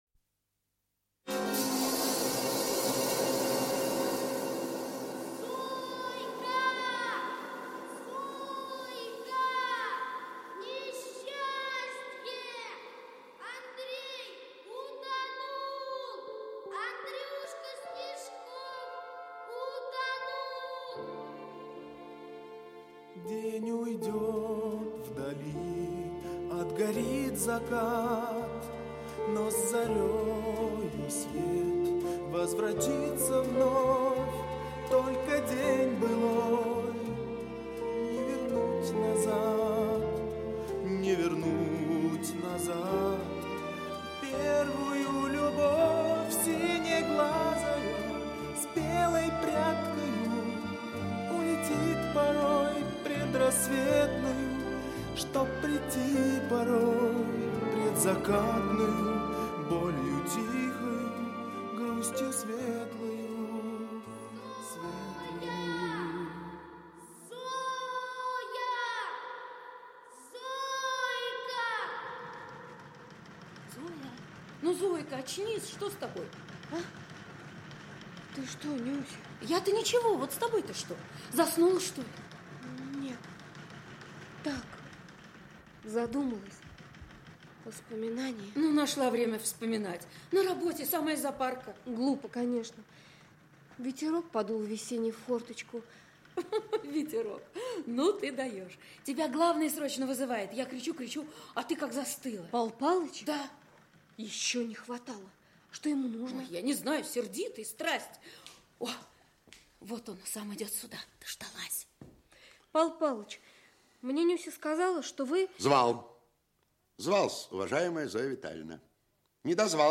Аудиокнига Баллада о некрасивой девушке | Библиотека аудиокниг
Aудиокнига Баллада о некрасивой девушке Автор Виктор Иванович Баныкин Читает аудиокнигу Актерский коллектив.